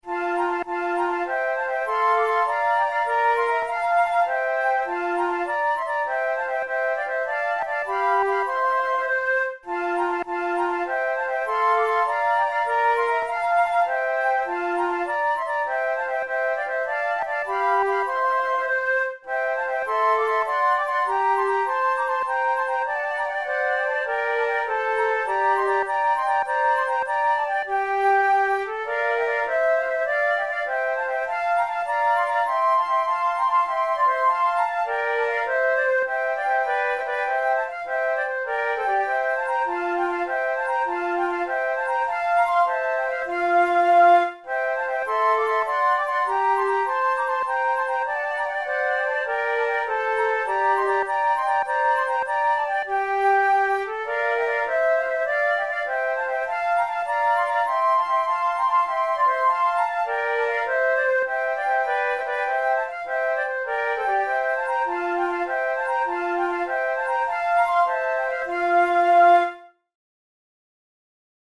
InstrumentationFlute trio
KeyF major
Time signature6/8
Tempo100 BPM
Baroque, Jigs, Sonatas, Written for Flute